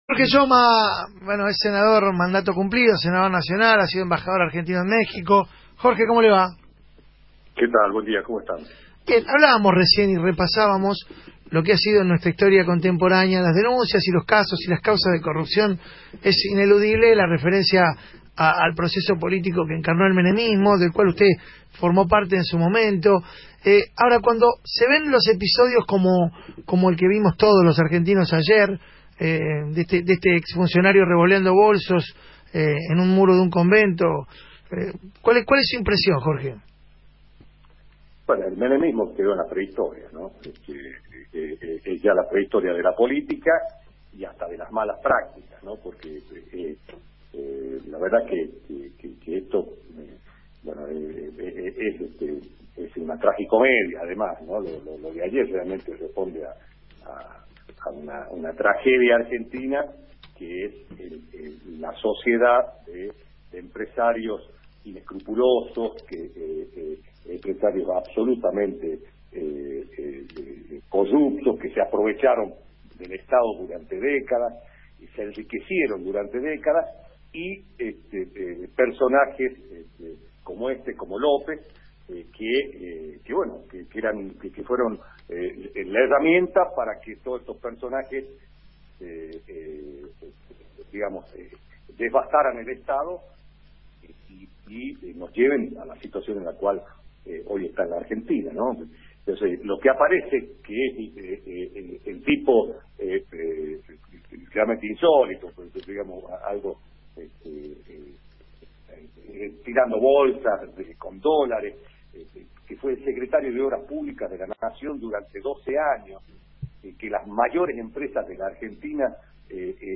En declaraciones al programa «La mañana de Radio 10» que se emite por Radio 10, Yoma se quejó porque se hace hincapié en la responsabilidad de los funcionarios públicos ante hechos de corrupción y no en los empresarios que forman parte del entramado, en la obra pública.